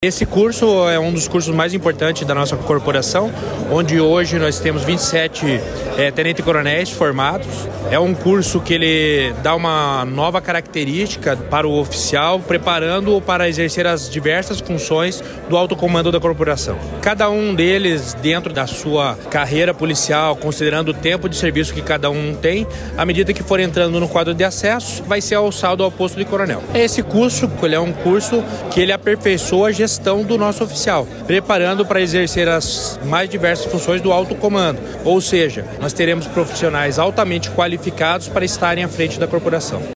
Sonora do comandante-geral da Polícia Militar, coronel Jefferson Silva, sobre a formatura de novos oficiais da PM e do Corpo de Bombeiros